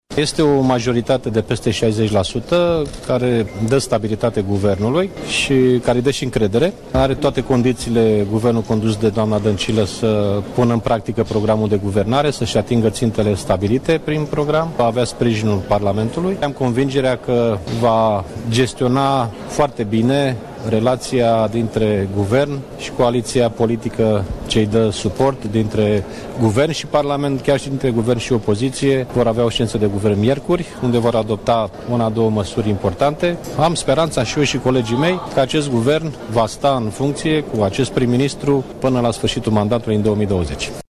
Președintele PSD, Liviu Dragnea a declarat că votul dă încredere și stabilitate guvernului: